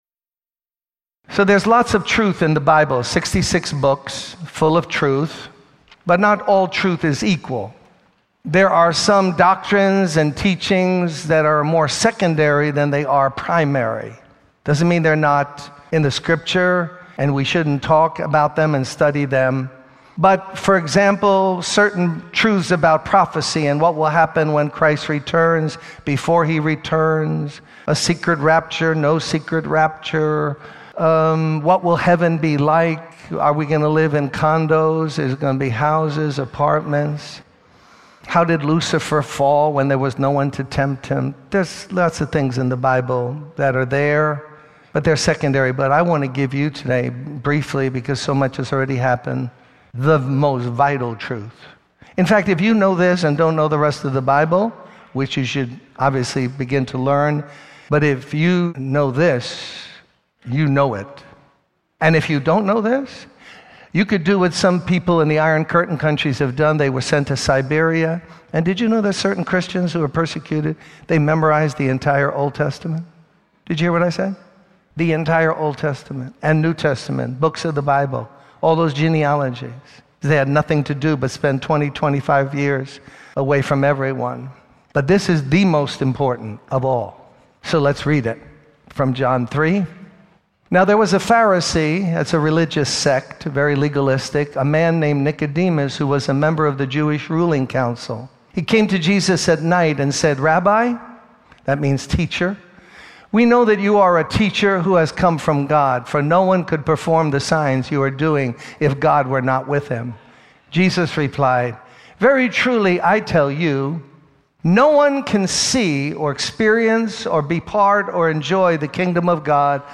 In this sermon, the speaker emphasizes the need for a personal transformation through Jesus Christ.